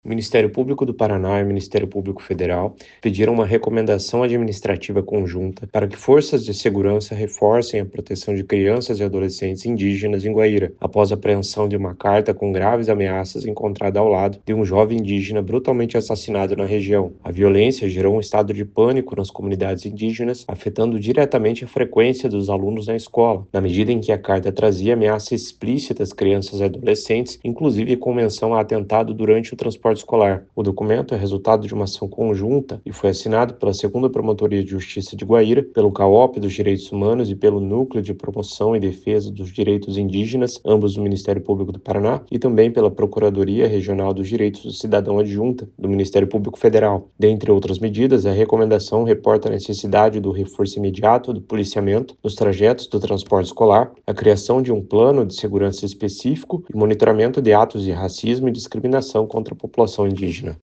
Ouça o que diz o promotor de Justiça Renan Góes de Lima.